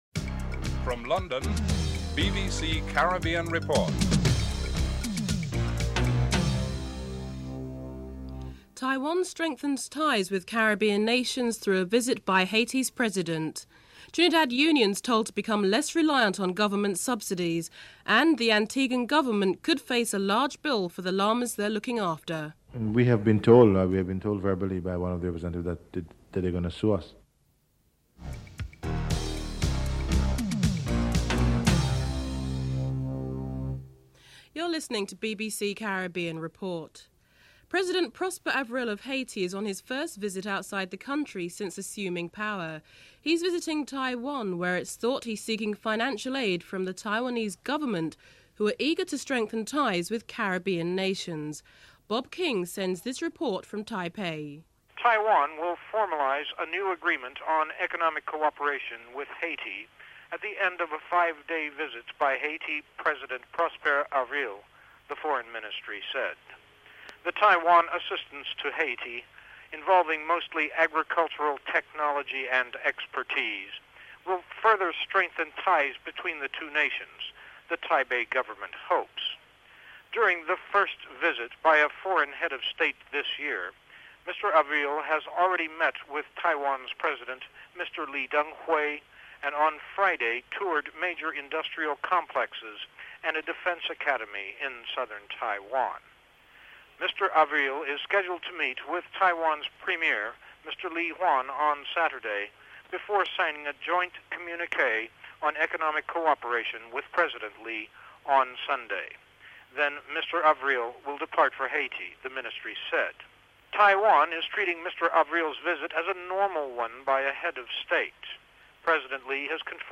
1. Headlines (00:00 - 00:36)